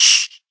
minecraft / sounds / mob / silverfish / hit1.ogg
hit1.ogg